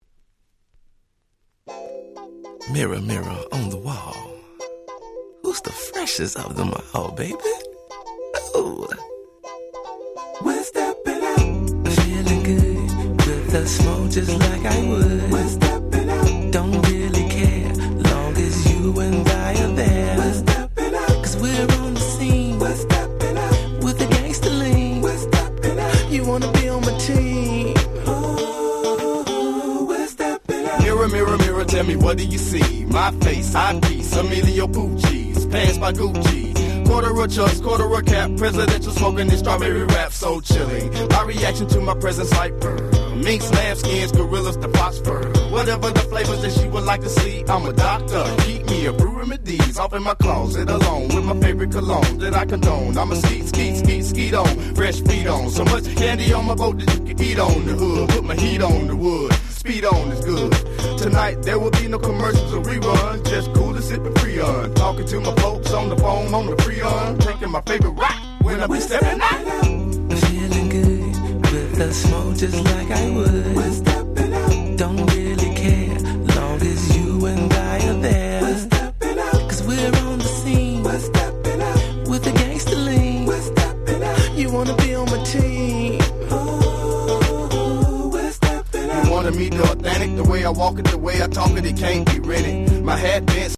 03' Smash Hit Hip Hop/West Coast Hip Hop !!